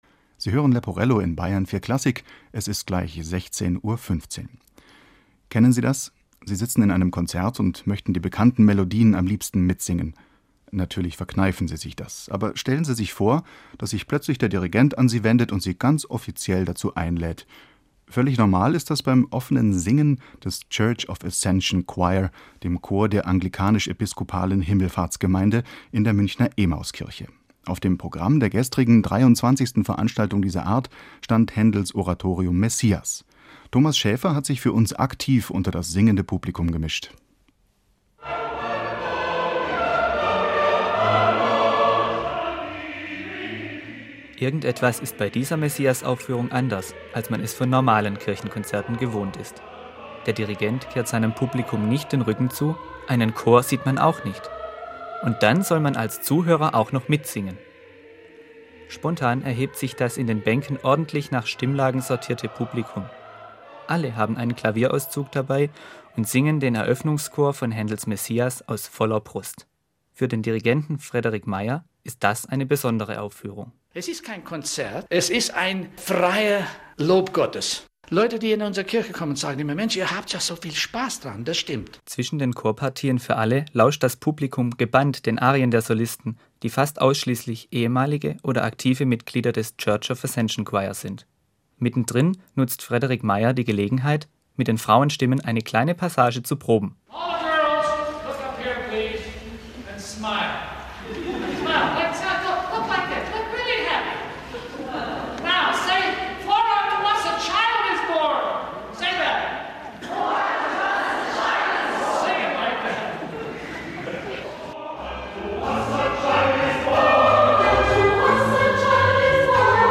Am 8.Dezember 2003 fand wieder einmal das jährliche "Open Sing" von Händels Messias in der Emmauskirche statt. Dieses Mal war es aber etwas ganz Besonderes, da der Bayerische Rundfunk anwesend war und am darauf folgenden Tag eine Reportage über die Veranstaltung sendete.